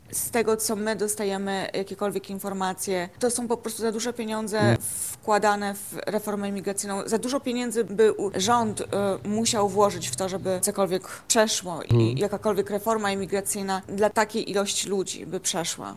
Na naszej antenie mówi także dlaczego nie ma szans na przeprowadzenie oczekiwanej reformy.